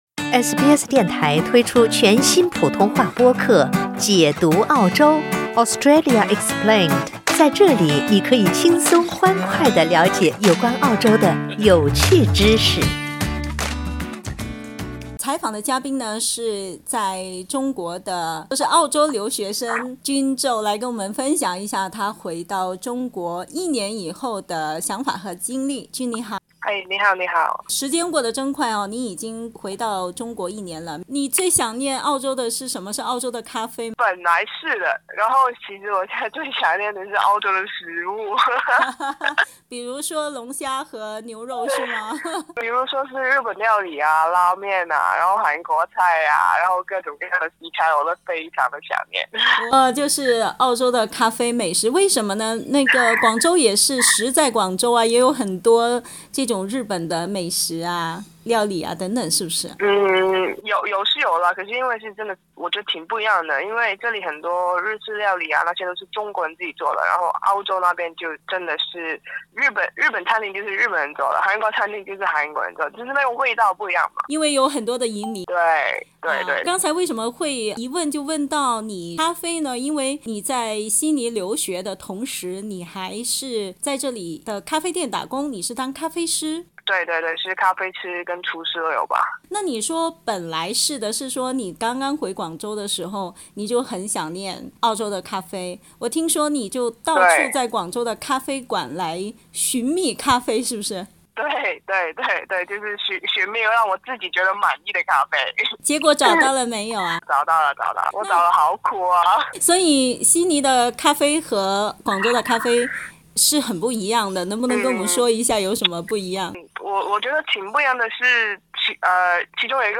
（请听报道） 澳大利亚人必须与他人保持至少 1.5 米的社交距离，请查看您所在州或领地的最新社交限制措施。